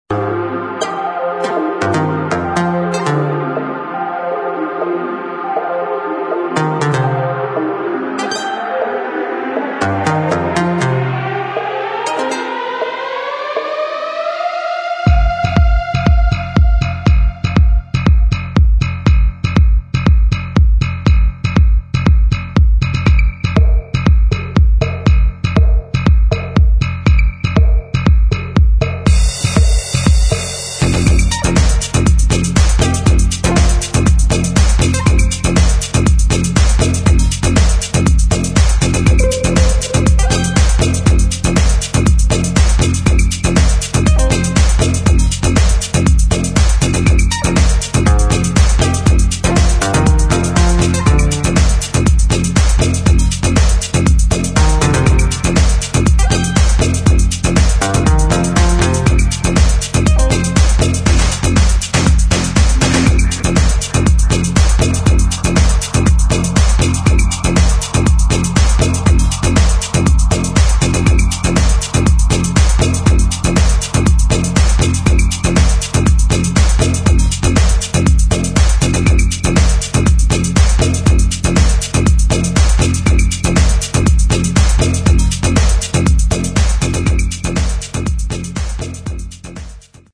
[ HOUSE / COSMIC ]